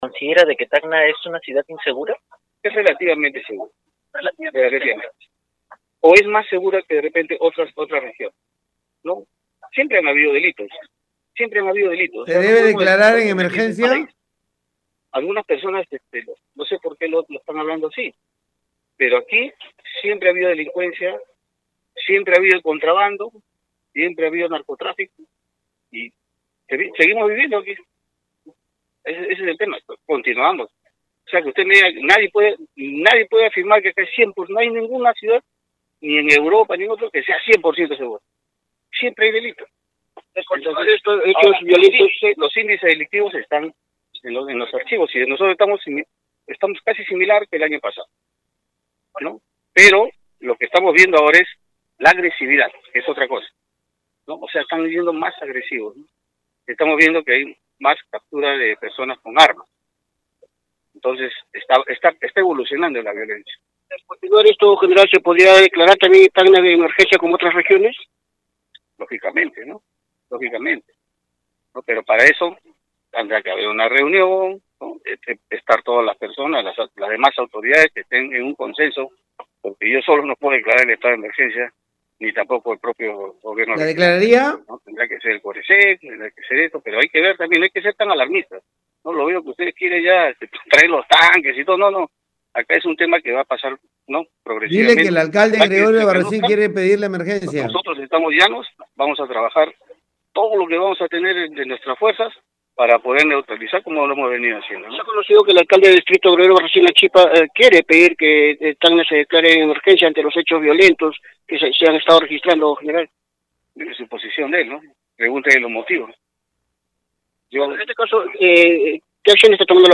Las declaraciones brindadas por el General fueron hechas al finalizar la ceremonia por el Día de la Mujer Policía y luego de ocurrir un caso de amenaza en el distrito Gregorio Albarracín, en el que una familia recibió en la puerta de su casa una corona de flores y bolsa con balas acompañada del mensaje “Devuelve lo que T has robado”, elaborado con letras recortadas.